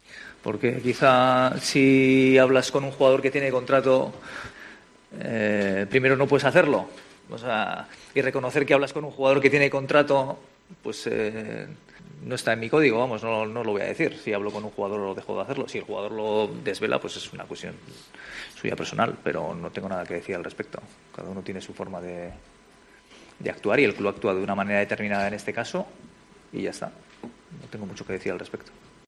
El preparador del equipo azulgrana señaló, en la rueda de prensa previa al partido liguero, que el Barça "intentará remontar" el próximo miércoles el 2-0 en contra que sufrió en Sevilla, si bien alerto de la importancia de sumar los tres puntos en Montilivi.